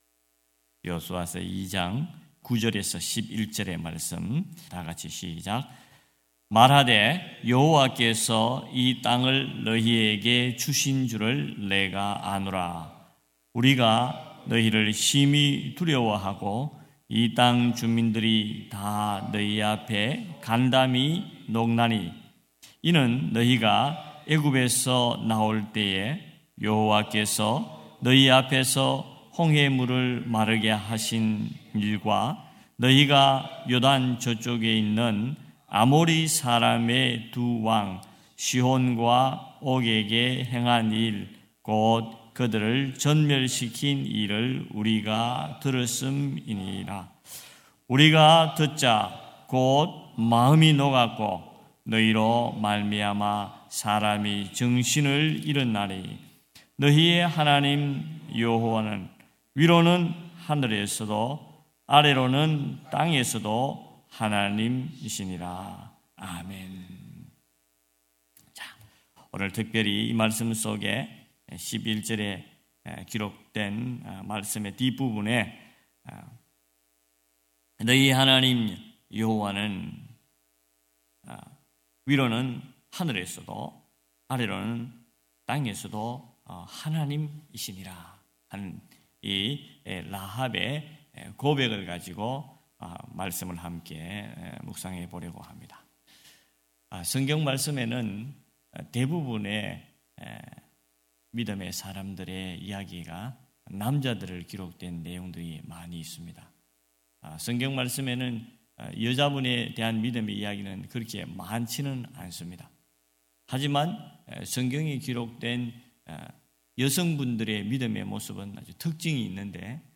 9.27.2025 새벽예배 여호수아 2장 9-11절